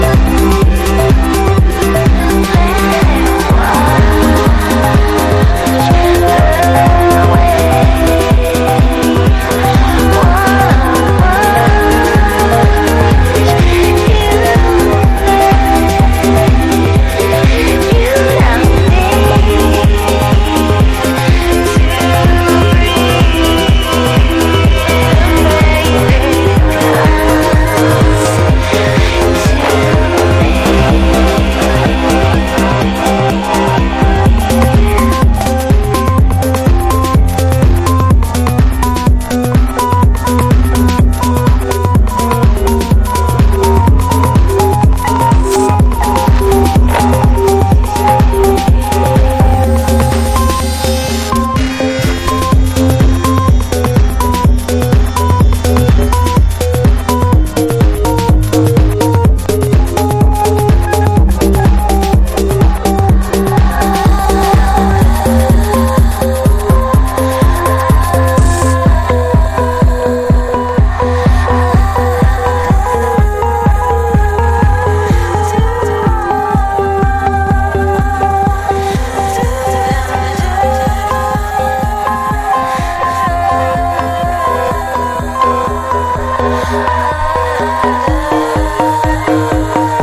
目玉はオリVER.のギターを活かしてズブズブのサイケ沼に引きずり込むA1。